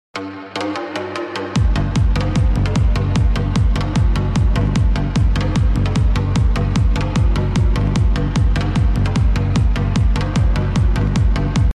Part 86｜YEAHBOX speaker Sonic Boom